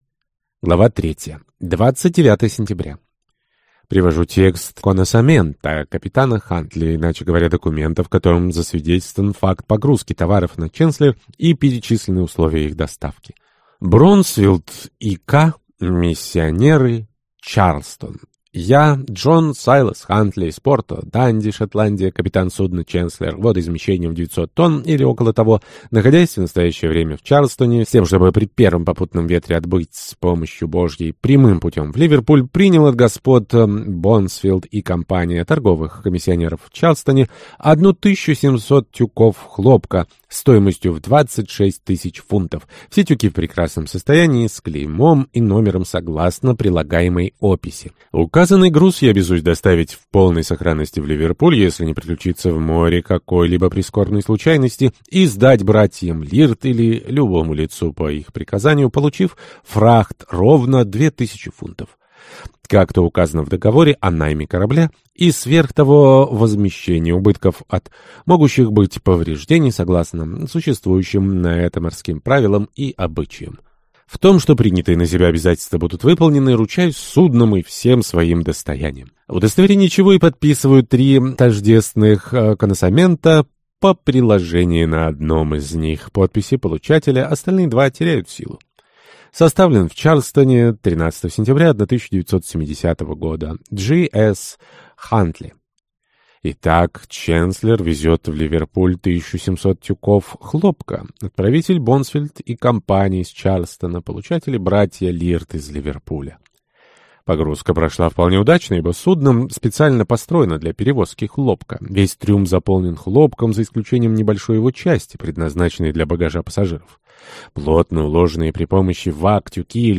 Аудиокнига «Ченслер» | Библиотека аудиокниг